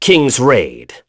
Lusikiel-Vox_Kingsraid.wav